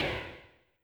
bendDown.wav